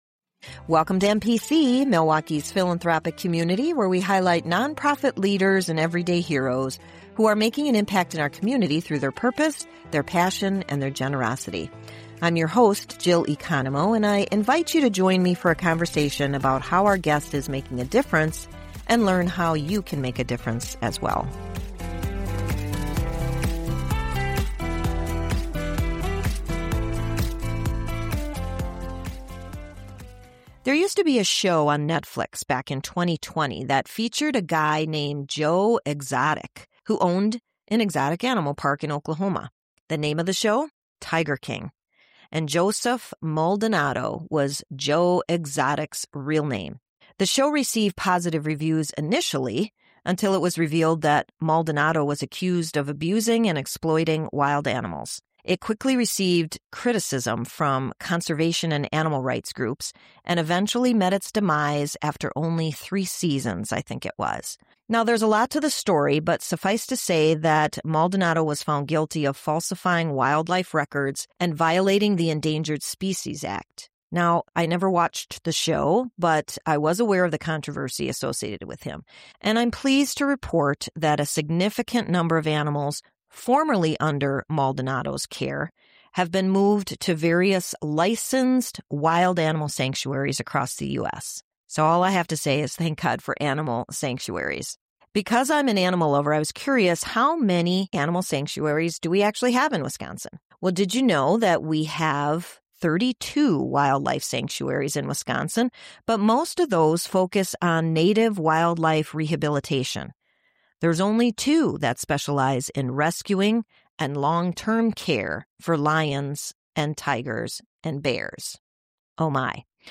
Guests include leaders of local non-profit organizations as well as individuals that have been inspired to create change.